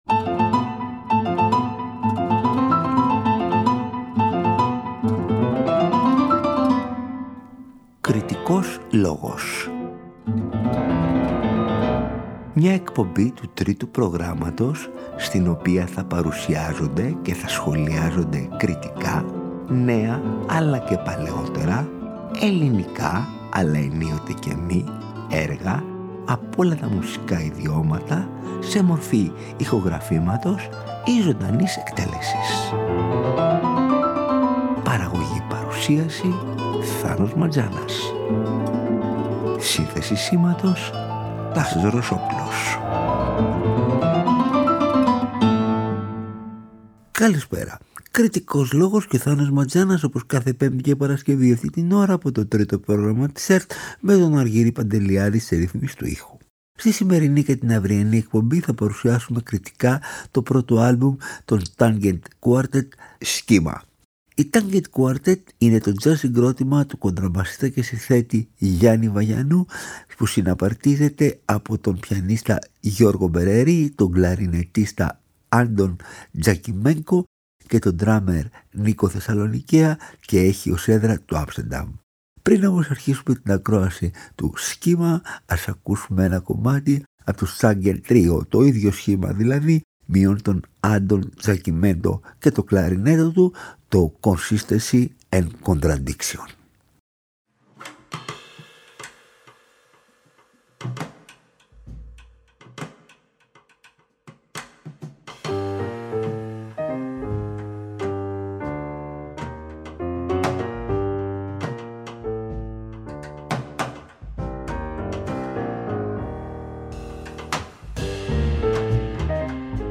Εκτός από το πολύ υψηλό αυτοσχεδιαστικό επίπεδο τους οι Tangent Quartet διακρίνονται και για το ότι ενσωματώνουν στα κομμάτια τους αρκετά στοιχεία της σύγχρονης, ακόμα και πρωτοποριακής μουσικής και για τον πειραματισμό τους με τις ενορχηστρώσεις και γενικότερα με τον ήχο.